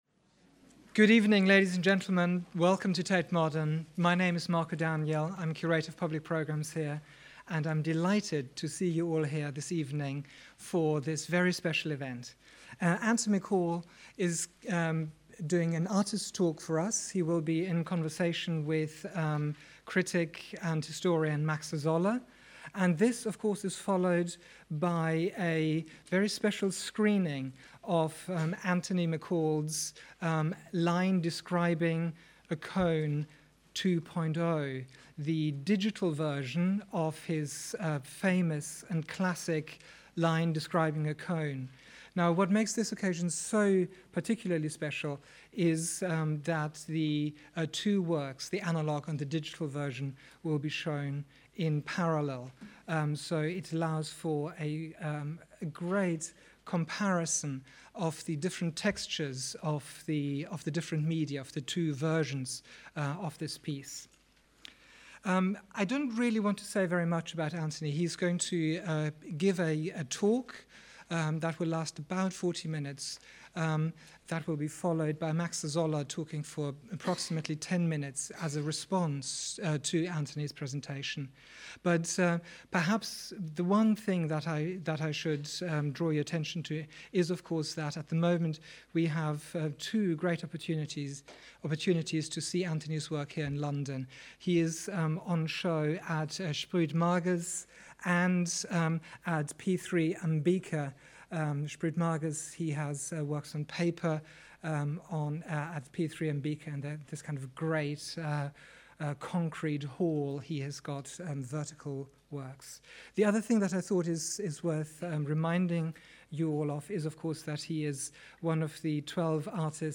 British-born, US-based artist Anthony McCall talks about new projects, including the recent vertical works, as well as public commissions.
Back to menu Tags: Talks and lectures Tate Modern